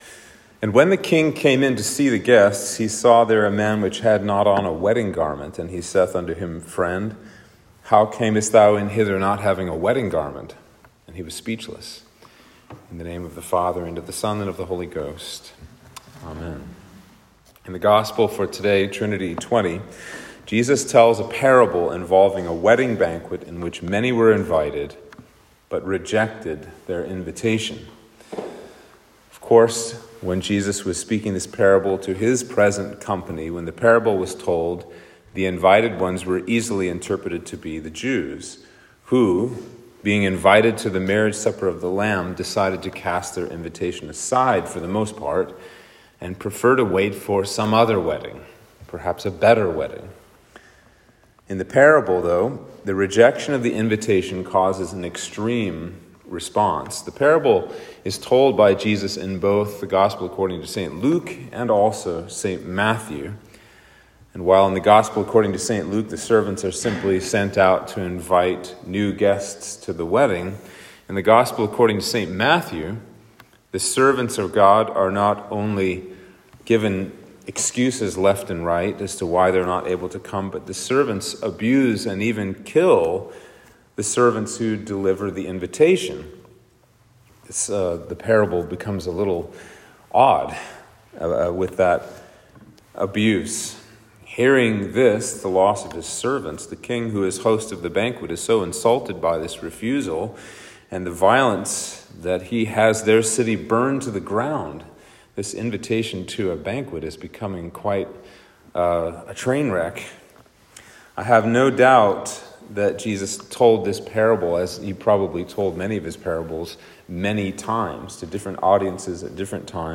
Sermon for Trinity 20